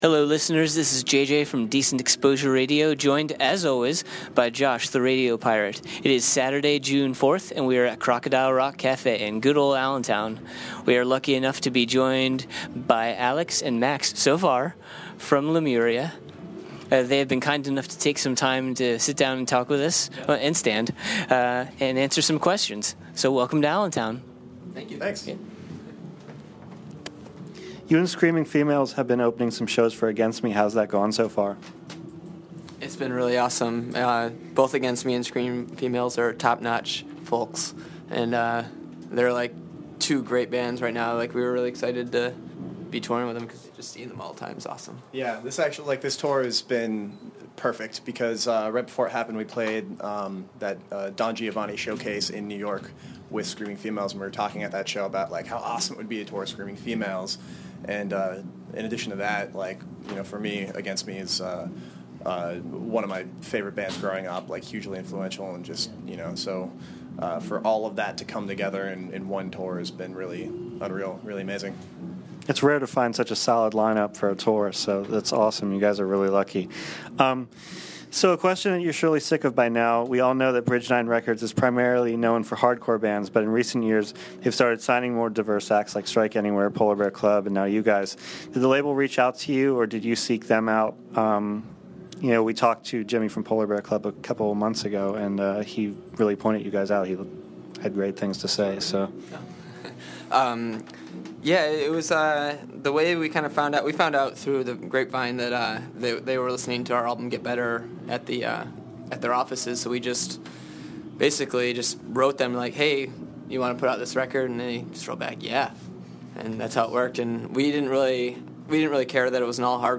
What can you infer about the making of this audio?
Here’s the interview we did with Lemuria when they opened for Against Me! at Crocodile Rock.